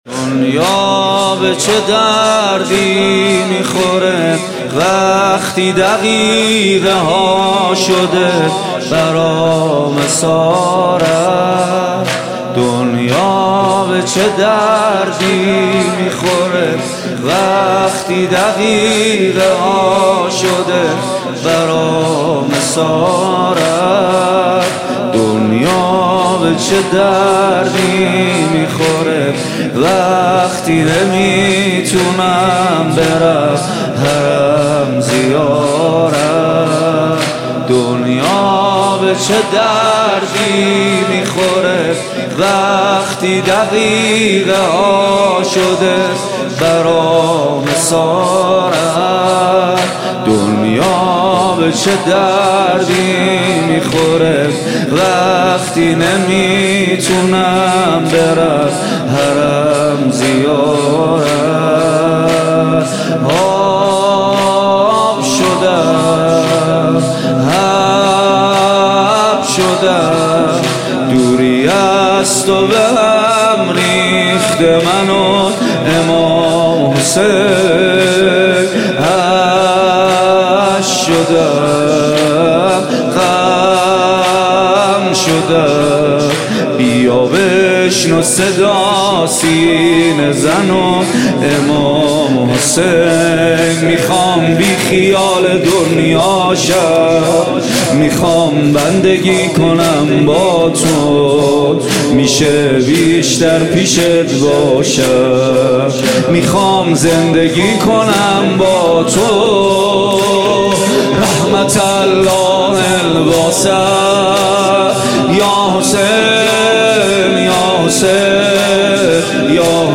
• نوحه و مداحی